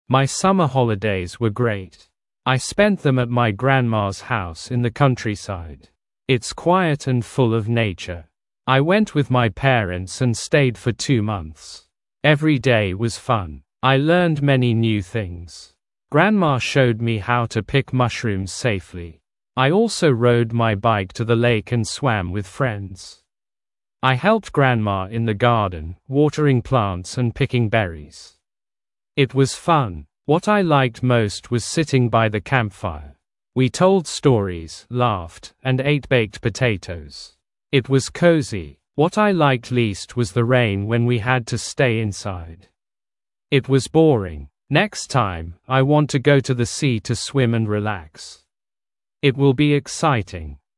Произношение русскими буквами: